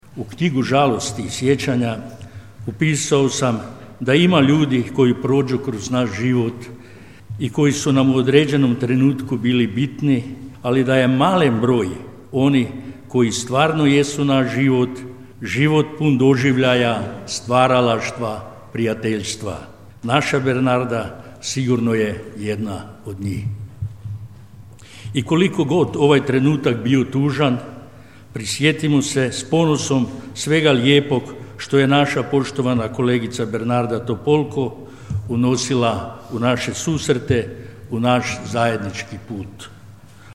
Skupština Međimurske županije održala je komemorativnu sjednicu u sjećanje na aktualnu županijsku vijećnicu i bivšu saborsku zastupnicu Bernardu Topolko (65).
Predsjednik Skupštine Međimurske županije Dragutin Glavina: